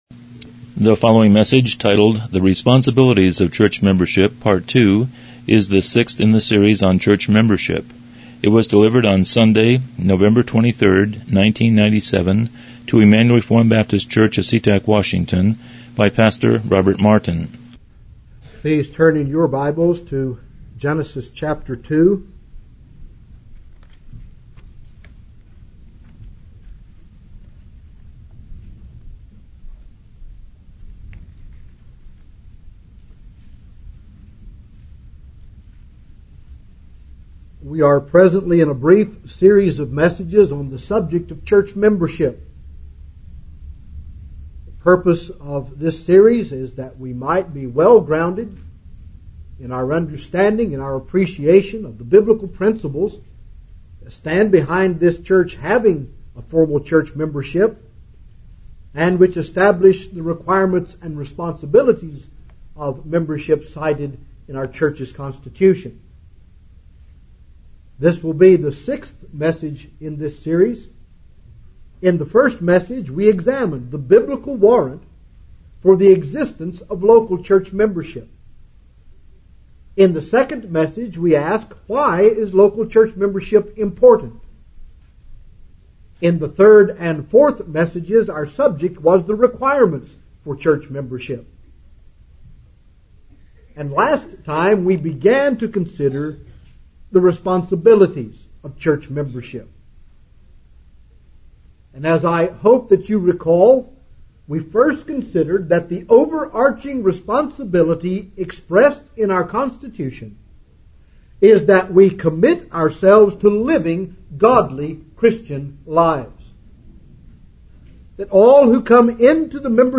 Series: Church Membership Service Type: Morning Worship « 05 Responsibilities of